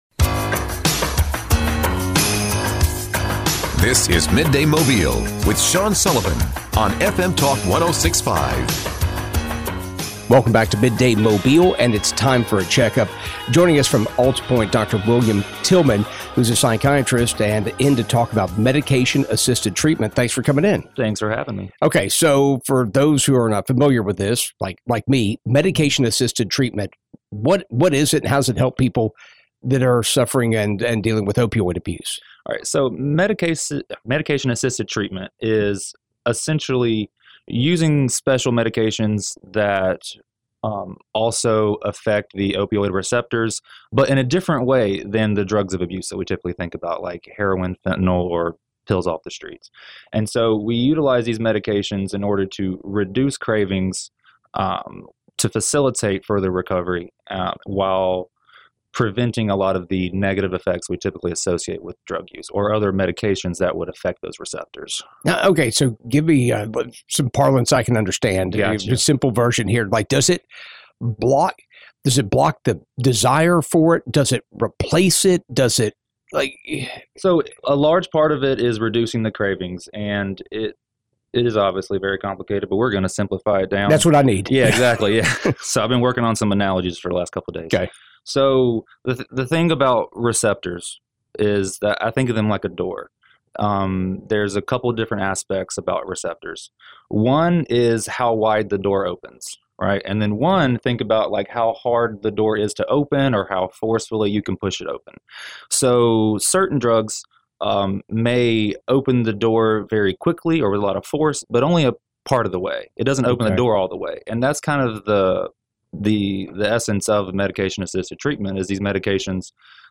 had a conversation